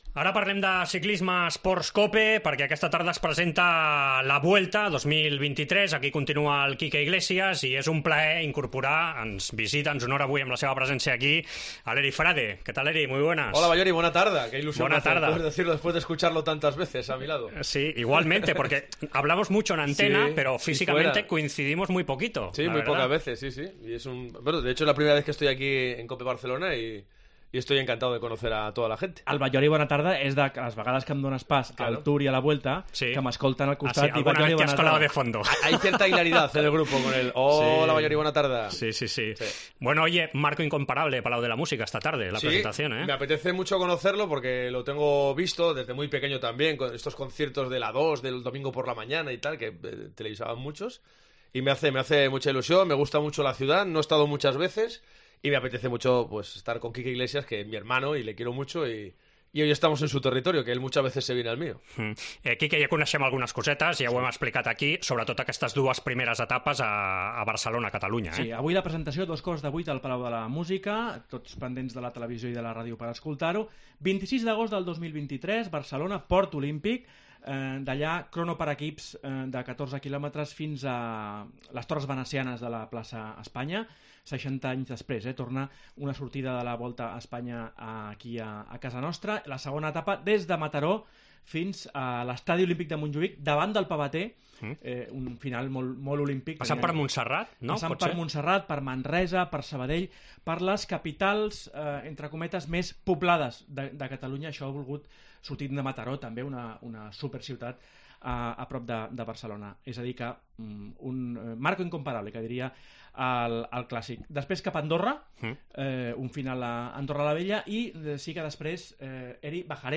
Rebem als estudis els especialistes en ciclisme de la COPE abans de la presentació de La Vuelta al Palau de la Música de Barcelona aquest dimarts.